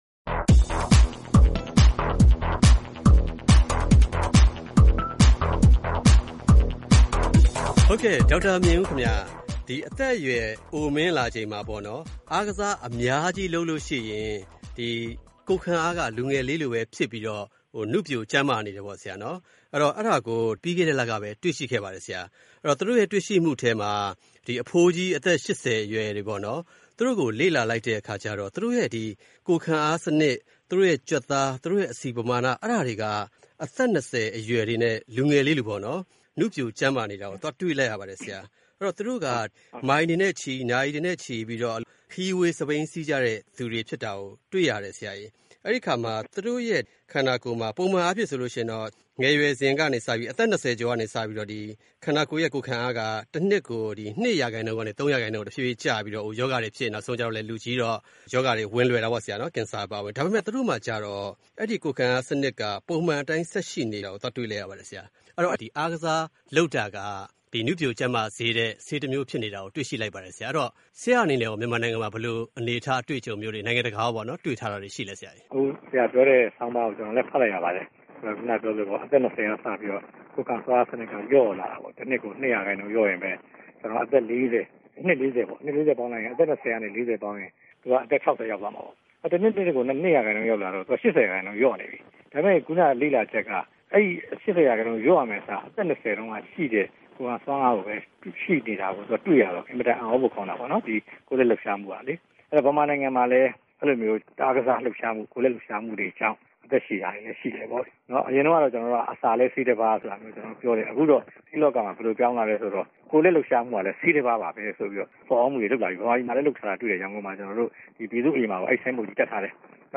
မေးမြန်းတင်ပြထားပါတယ်။